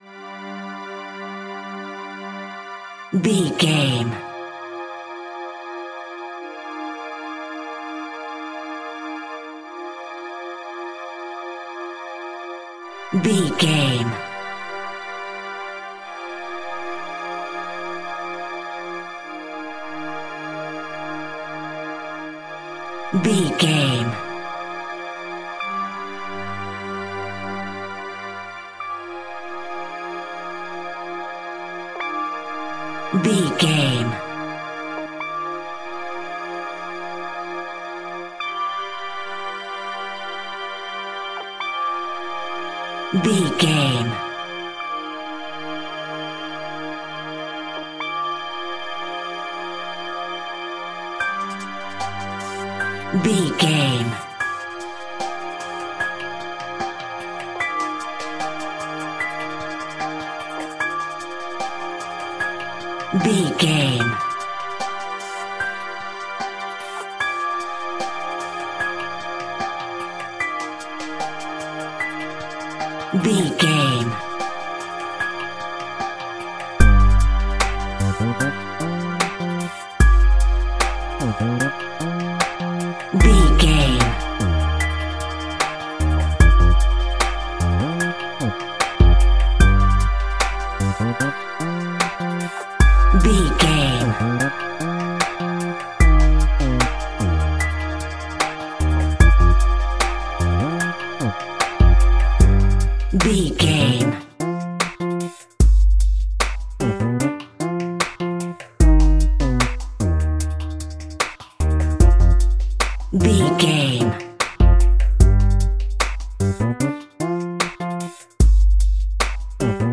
Zombie Tripping Music Cue.
In-crescendo
Aeolian/Minor
Slow
ominous
eerie
synthesizer
percussion
Horror synth
Horror Ambience